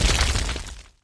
evil_dragon_hit1.wav